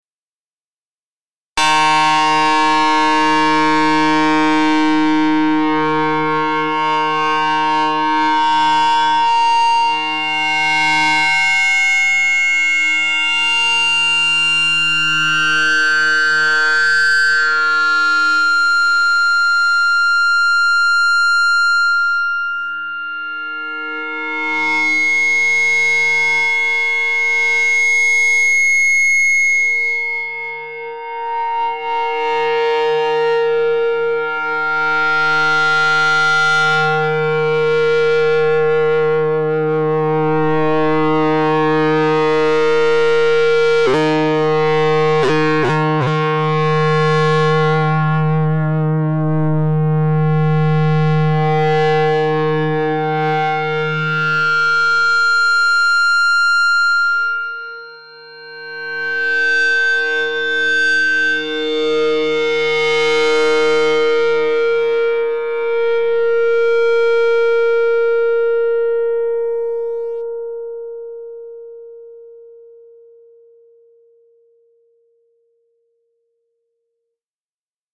For all examples, the asymmetric distortion model was used, as it produced the best distorted tone.
Demonstration of Feedback in Monophonic Model
One note is played while the feedback delay is adjusted to demonstrate dynamic feedback effects.